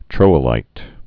(trōə-līt, troilīt)